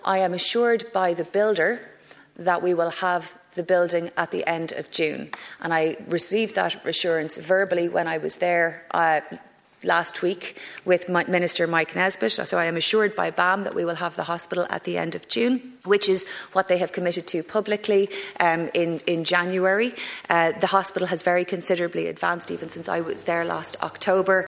Minister Carroll MacNeill, says she expects the new hospital to be fully open next summer: